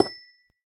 Minecraft Version Minecraft Version 1.21.5 Latest Release | Latest Snapshot 1.21.5 / assets / minecraft / sounds / block / amethyst / place4.ogg Compare With Compare With Latest Release | Latest Snapshot